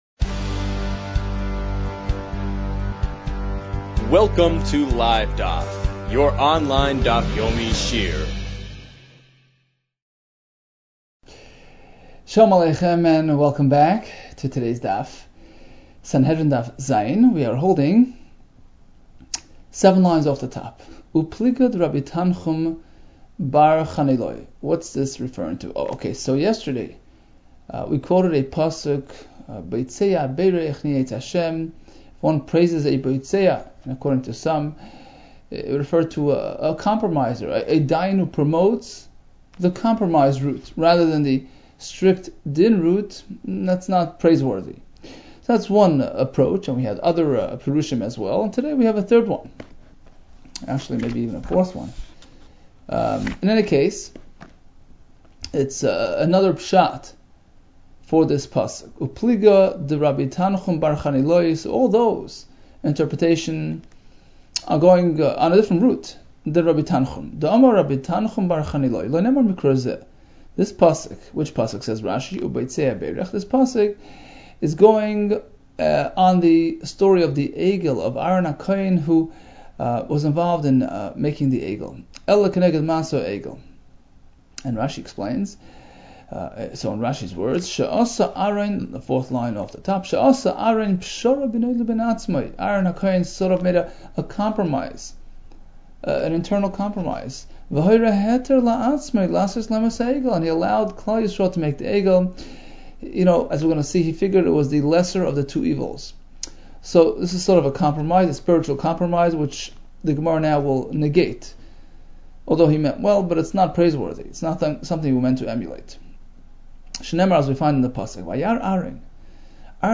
Sanhedrin 7 - סנהדרין ז | Daf Yomi Online Shiur | Livedaf